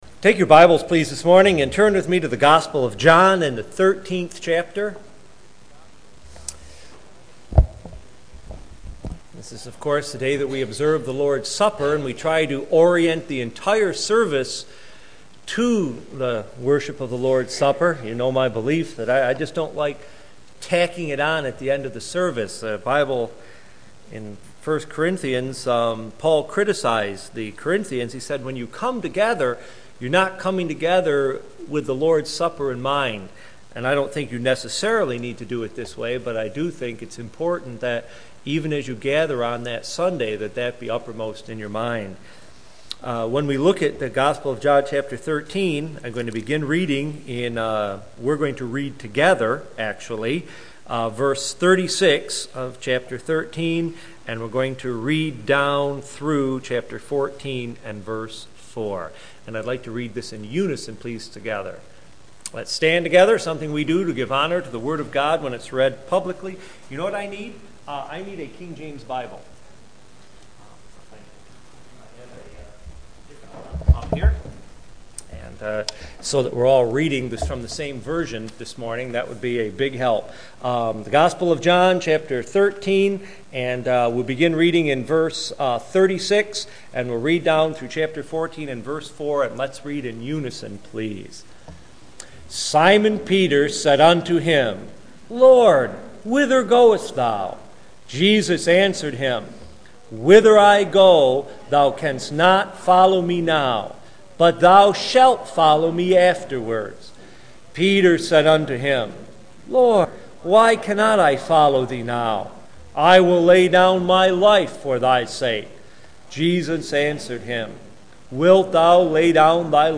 John 13:36-14:4 – Sermons